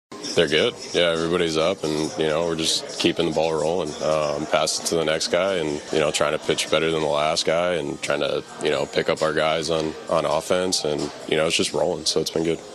Burrows likes the way the relief corps works.